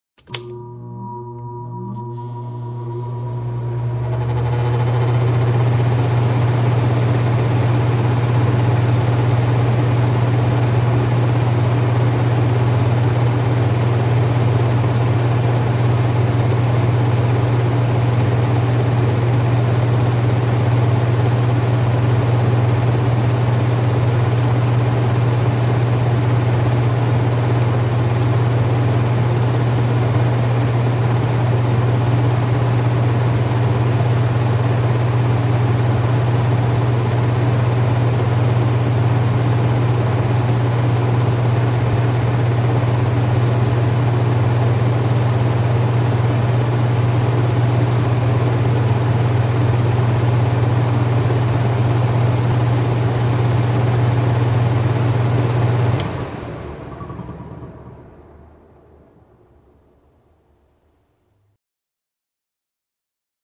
Звуки бытовой техники
Наружный блок кондиционера